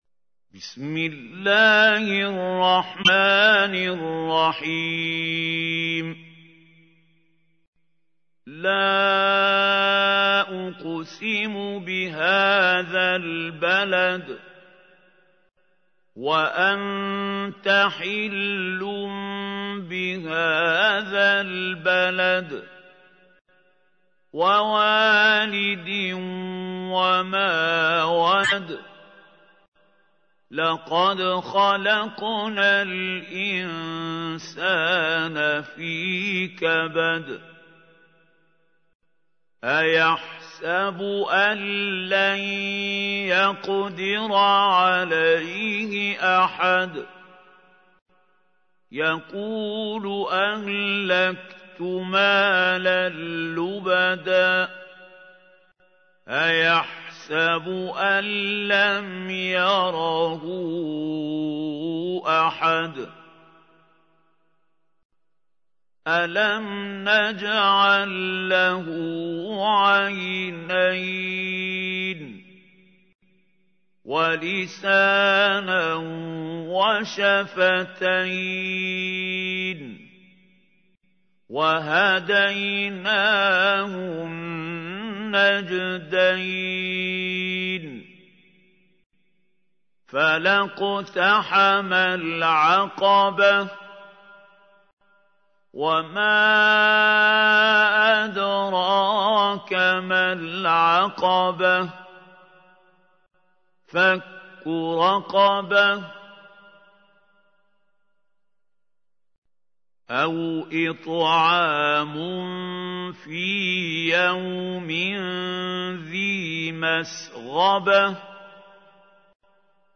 تحميل : 90. سورة البلد / القارئ محمود خليل الحصري / القرآن الكريم / موقع يا حسين